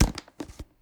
Objects, Container, Plastic Lunch Box, Squeaky Plastic, Handle, Grab 03 SND114930 S10.wav